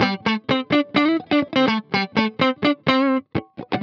20 GuitarFunky Loop A.wav